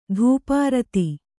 ♪ dhūpārati